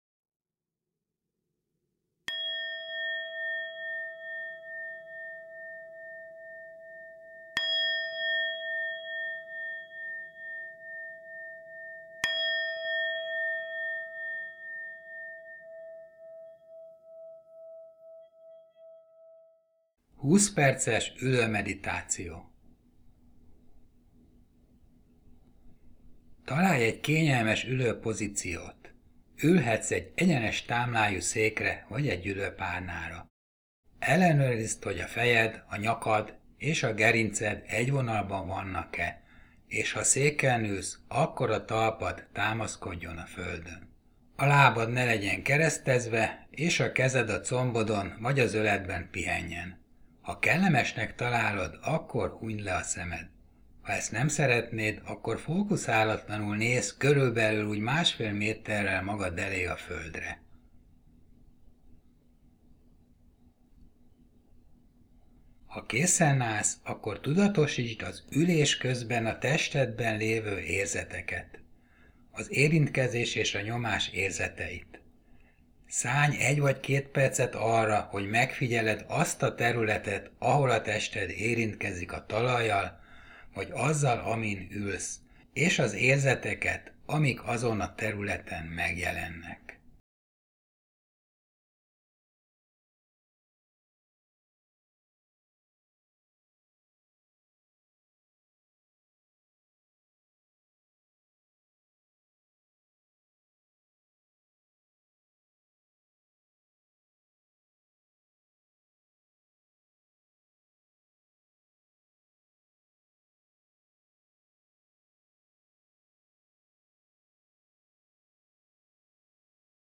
20-perces-ulomeditacio-.mp3